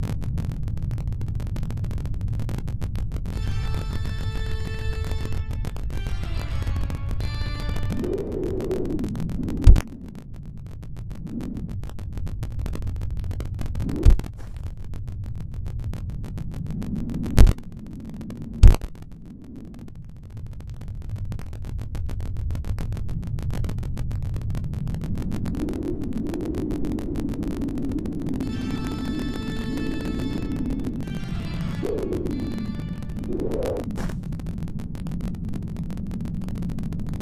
Weird Sound Corruption
I still get this weird static-y sound.
Second Life is the only place I hear it.
You have some sound interpolation issues with SL and your card and it is causing that static noise.
BadSoundFX_SL.wav